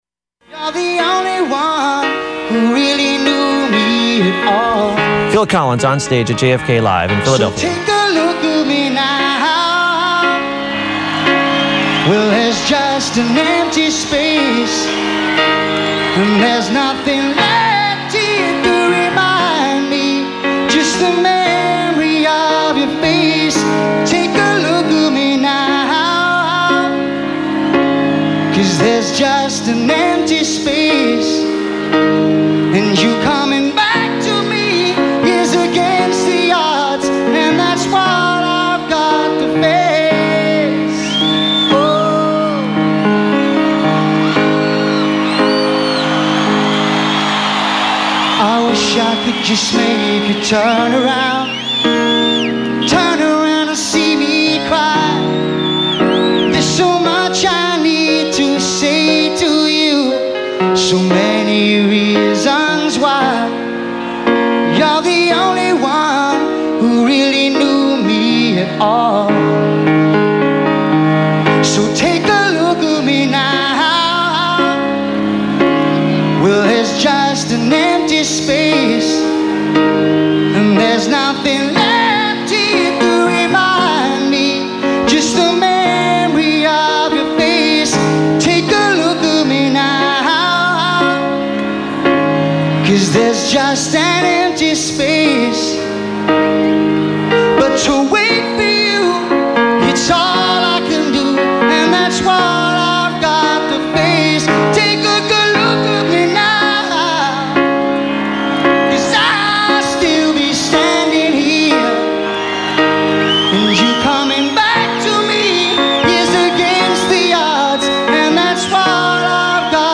who was seated at a gleaming black grand piano.
accompanied only by the piano
At the conclusion of these two solo performances